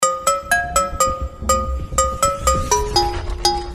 • Качество: 320, Stereo
без слов
короткие
ксилофон